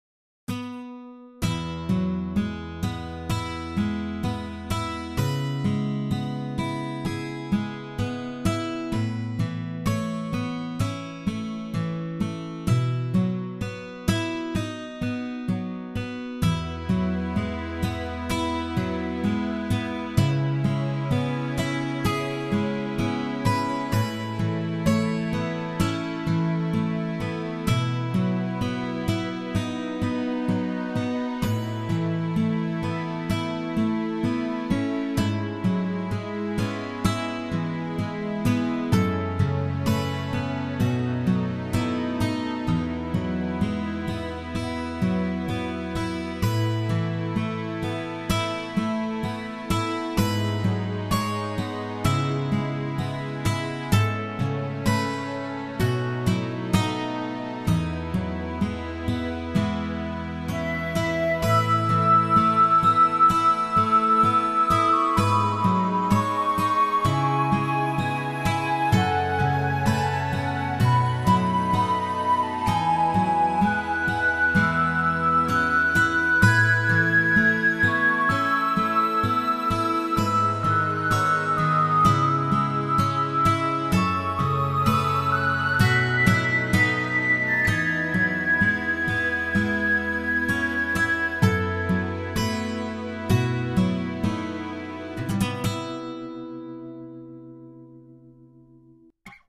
この曲を聴いていると何故か昔を思い出すような感じがします。